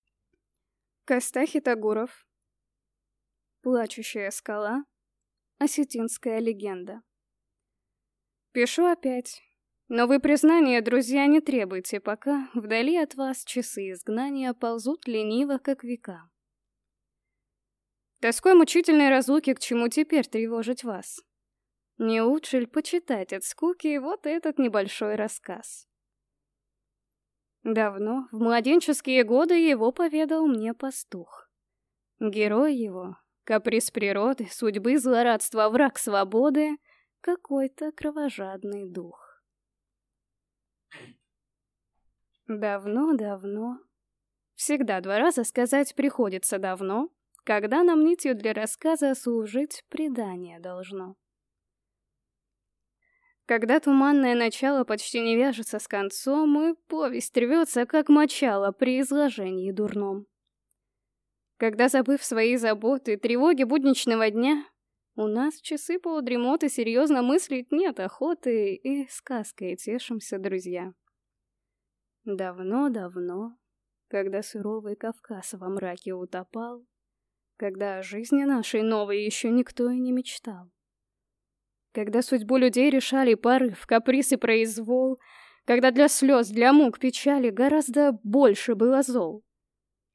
Аудиокнига Плачущая скала | Библиотека аудиокниг